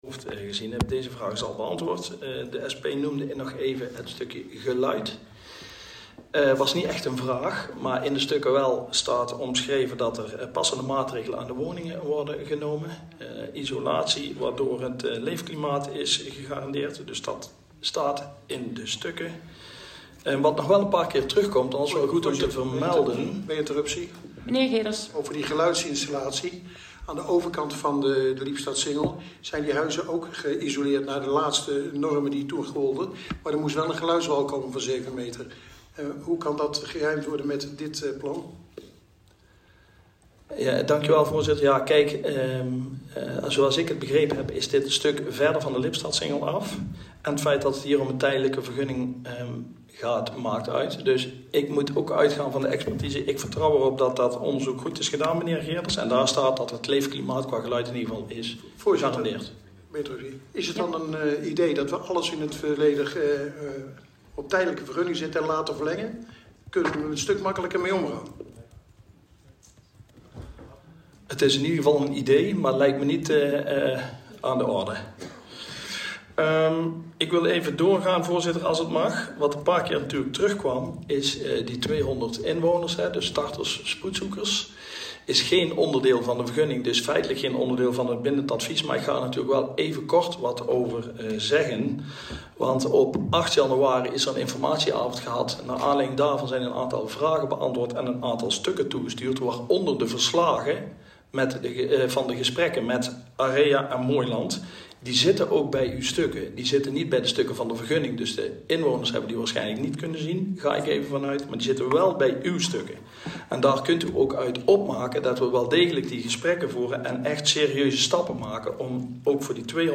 Helaas zijn er haperingen bij de video-opnames van de vergadering die hieronder te vinden zijn. Deze zitten (met name) in het einde van de opnames.